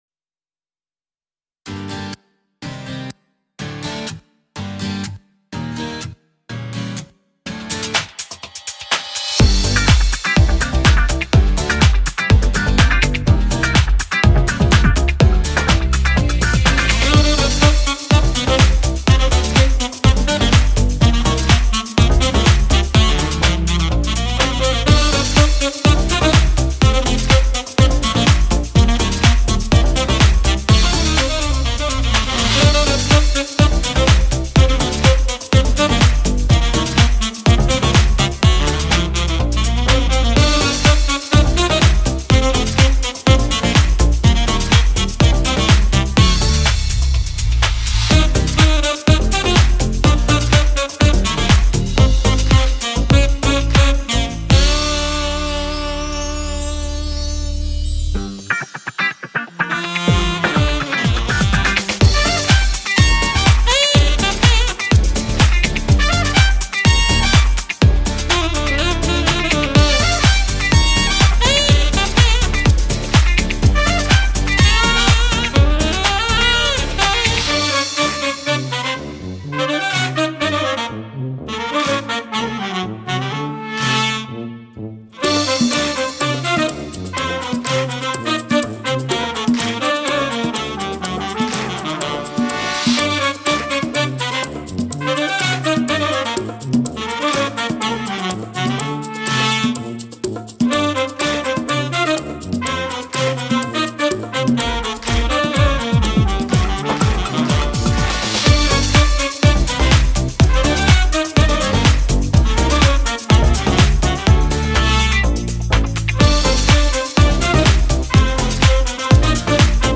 tema dizi müziği, neşeli eğlenceli enerjik fon müziği.